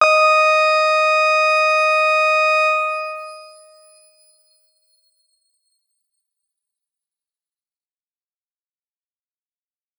X_Grain-D#5-pp.wav